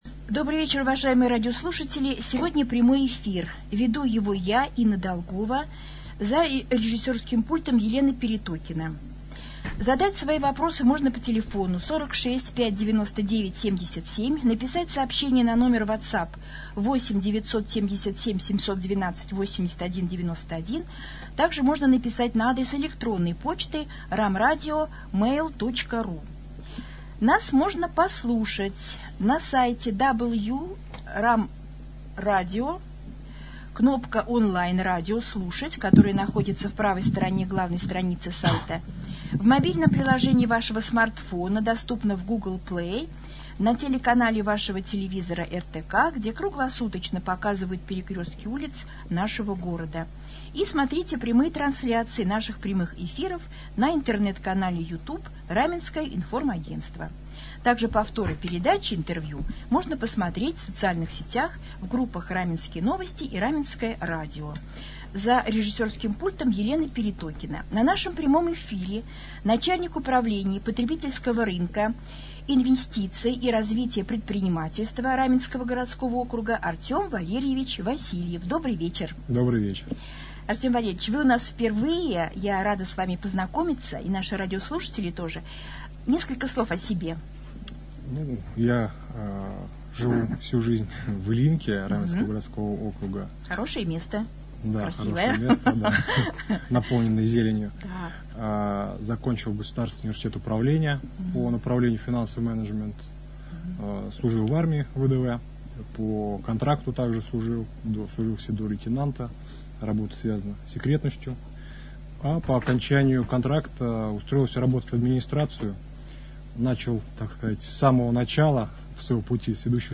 3 июня в прямом эфире на Раменском радио Артем Васильев, начальник Управления потребительского рынка, инвестиций и развития предпринимательства администрации Раменского городского округа, рассказал о том, какая работа ведется в сфере поддержки малого и среднего предпринимательства в период пандемии, куда обращаться за помощью покупателям, когда начнут работу ярмарки выходного дня и как в муниципалитете возобновляется работа предприятий после снятия ряда ограничений.
prjamoj-jefir.mp3